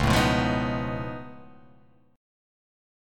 Db+9 chord